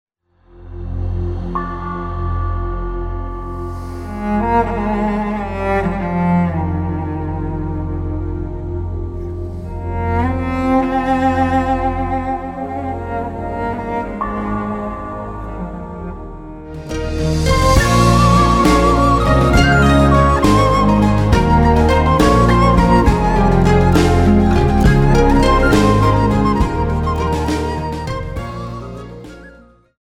11th Century, France
besides various harps